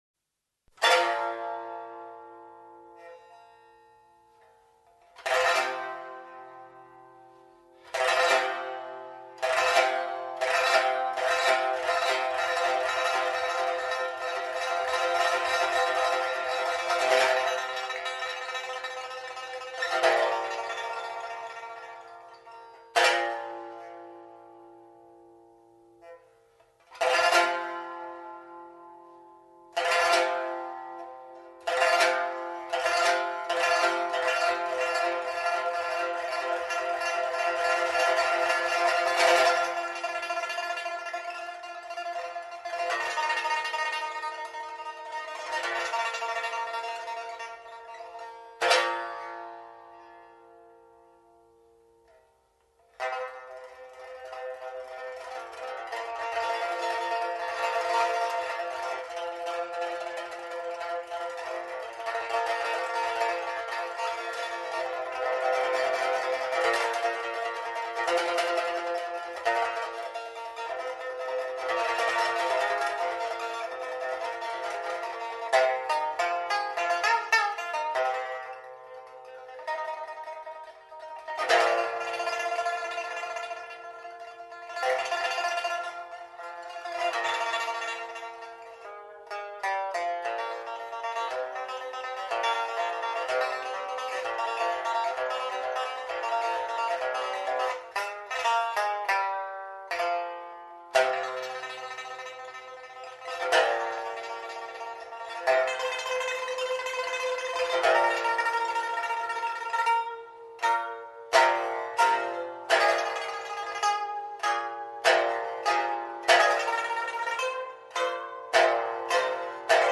Chinese Music: The Conqueror Removing His Suite of Armour - PiPa Solo 霸王卸甲 琵琶獨奏
bawangxiejia_pipa.mp3